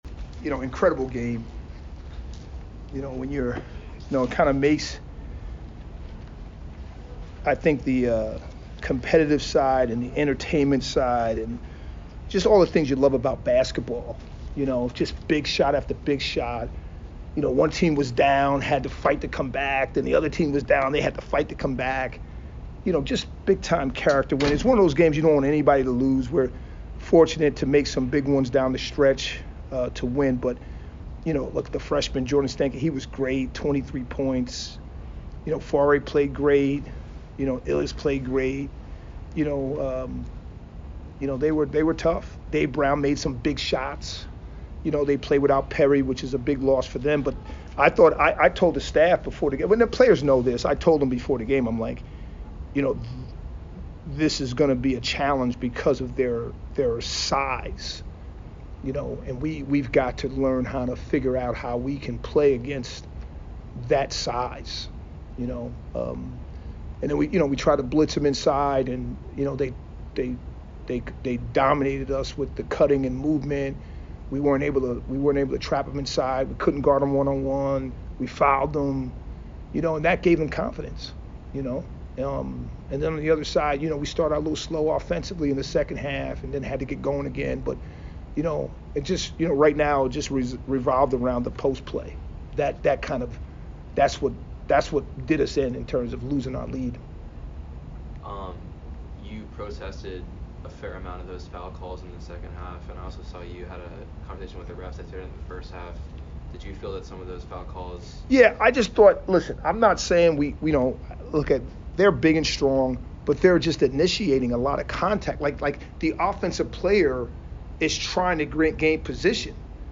Loyola Maryland Postgame Interview